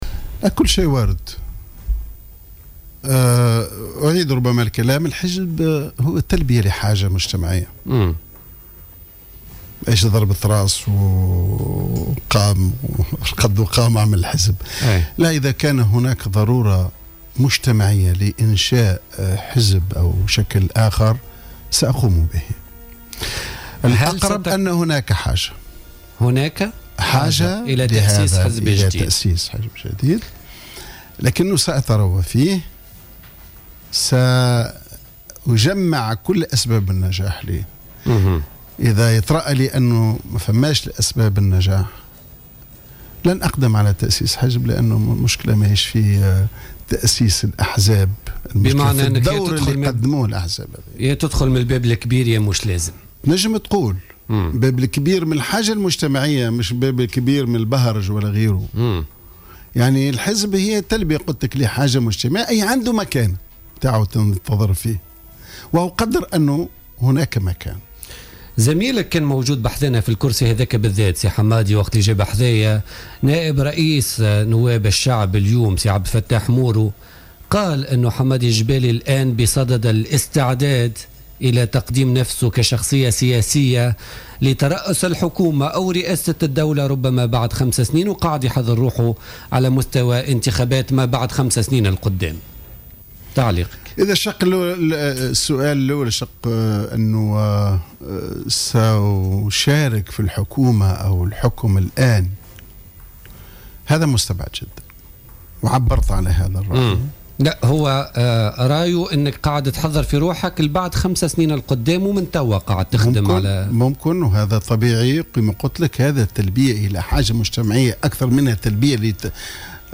قال الأمين العام السابق لحركة النهضة حمادي الجبالي ضيف برنامج "بوليتيكا" اليوم إن إمكانية تأسيسه لحزب سياسي جديد تبقى واردة.